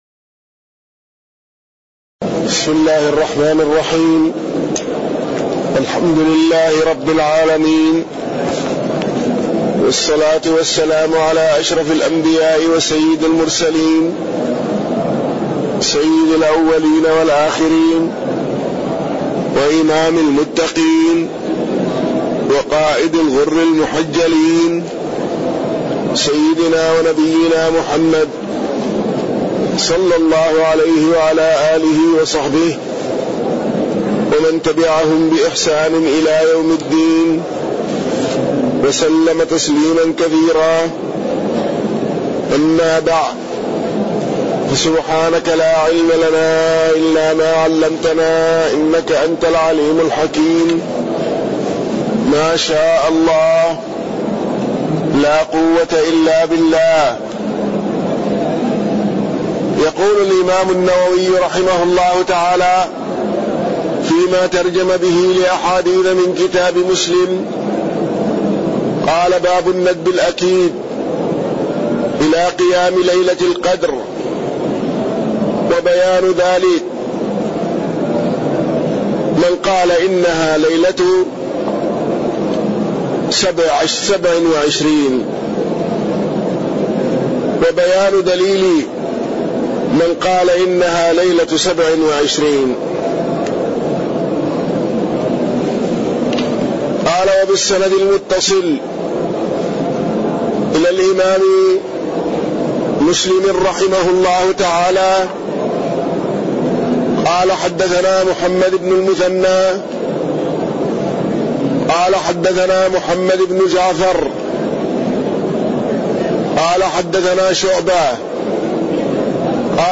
تاريخ النشر ١٢ محرم ١٤٣١ هـ المكان: المسجد النبوي الشيخ